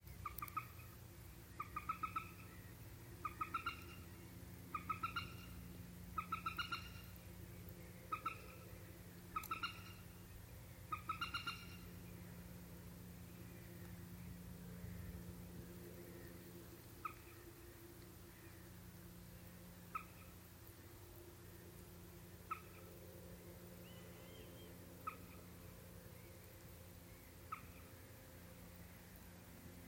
Ūdensvistiņa, Gallinula chloropus
StatussDzirdēta balss, saucieni